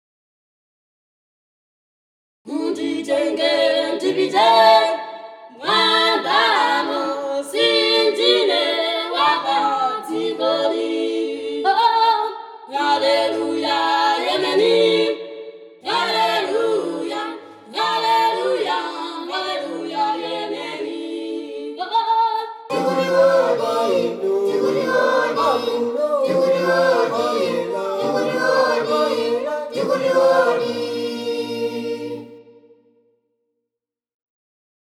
Bei den Voices sind einige Stimmen aus Afrika hinzu gekommen.
ethno-world-voices-sa.mp3